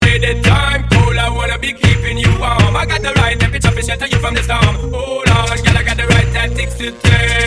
Dynamic Time & Pitch Shifting
Temperature_Time_Shifted_Faster.mp3